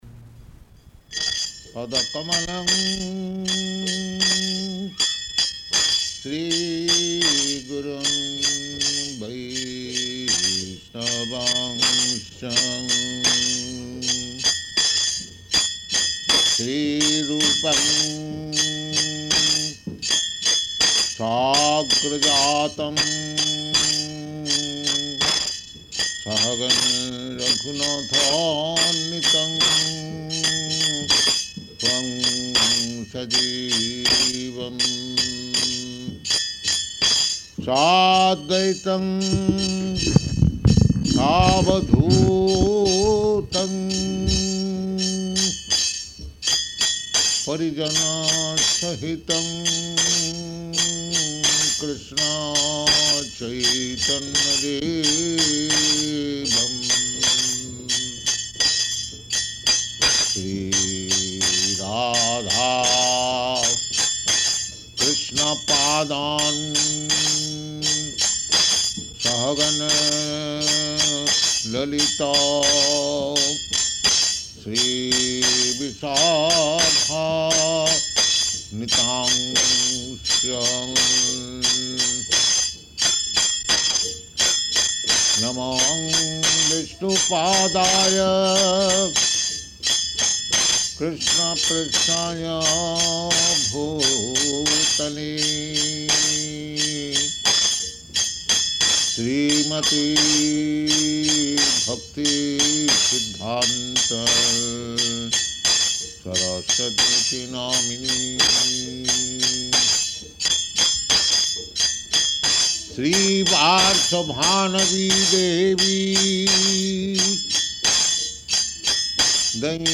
Type: Lectures and Addresses
Location: Hawaii
Prabhupāda: [sings:]